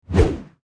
skyrim_mace_swing1.mp3